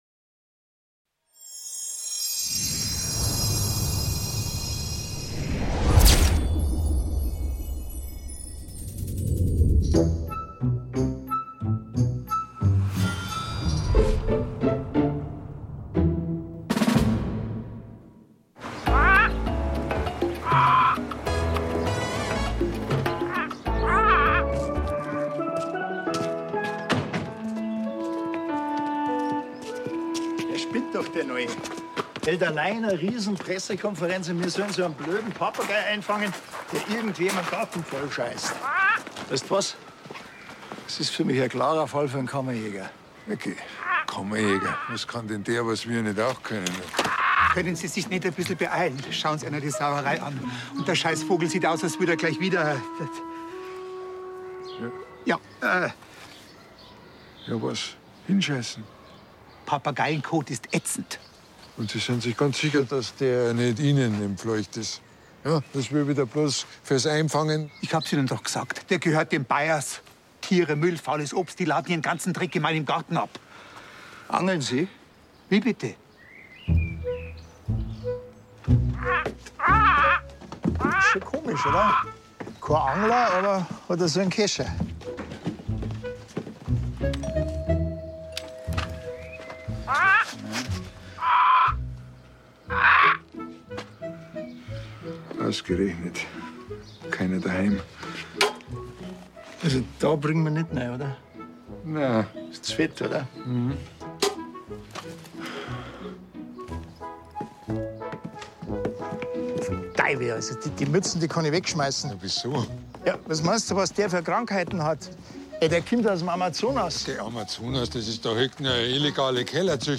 S01E01: Kleine Fische, große Fische ~ Hubert und Staller Hörspiel Podcast